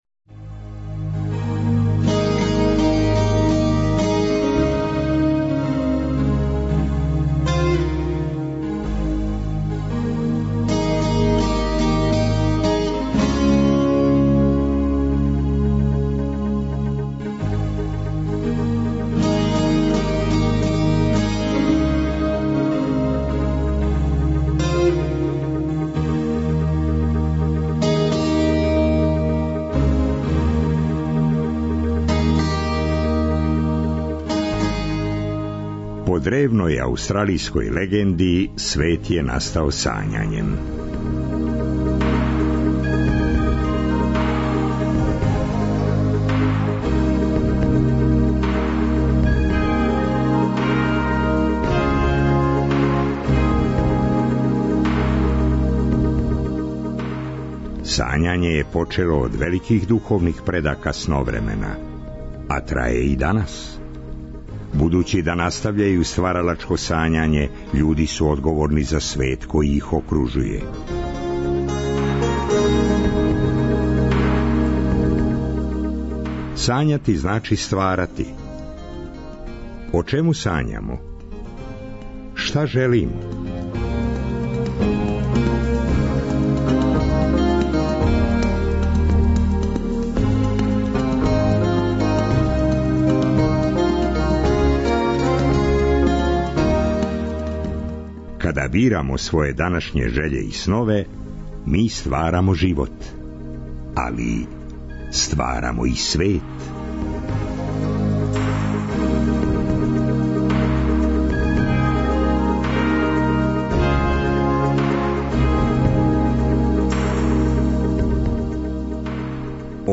Младе уметнице испричале су нам о стварању филма "Непослушни", колико је дубоко тај подухват утицао на њихове животе и надахнуо освајање напуштеног биоскопа. У трећем и четвртом сату емисије - текстови по избору наших саговорница.